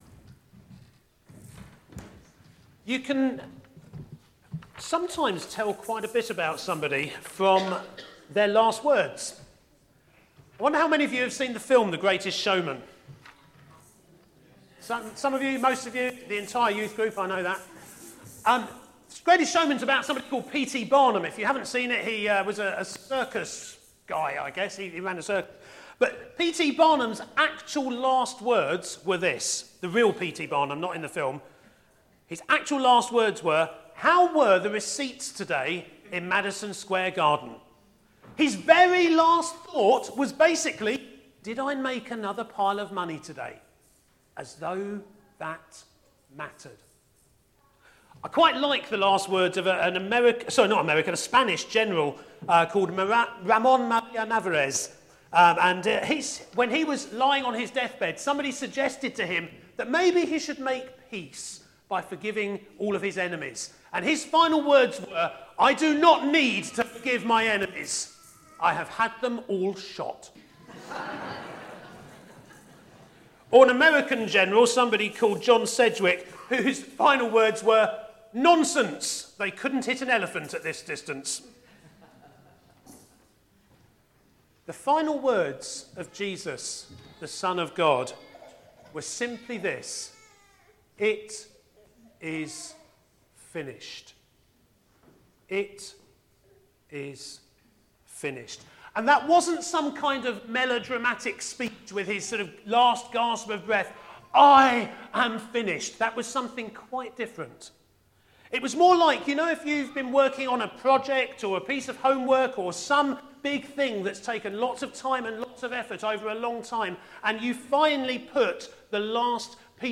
A message from the series "Stand Alone Sermons (2018)."